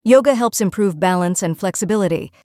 /tts/examples_azure/y/